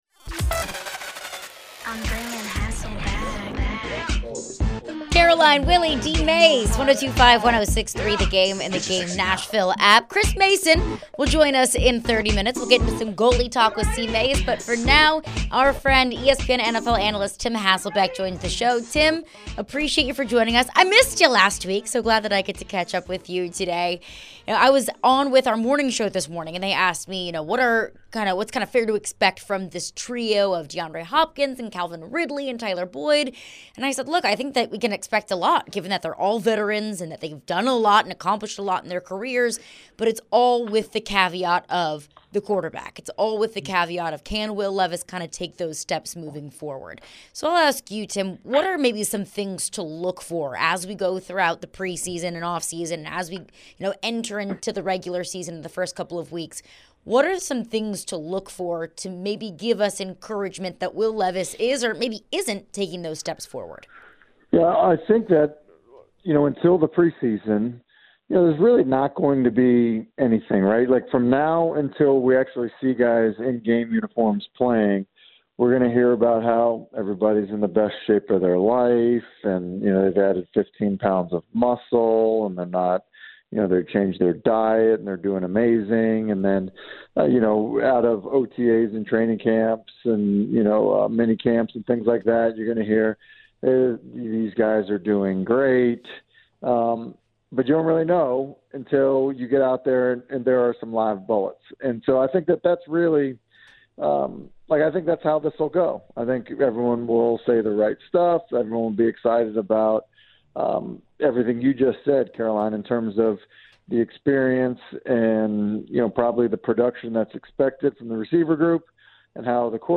ESPN’s Tim Hasselbeck joined the show. Tim was asked about the Titans roster and the new addition of Tyler Boyd. Tim also was asked about the chemistry between a wide receiver and a quarterback, especially for a guy like Will Levis.